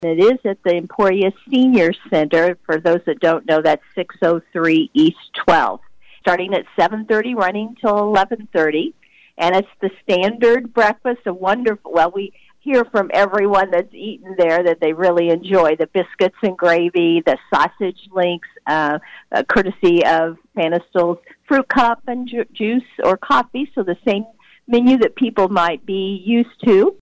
KVOE News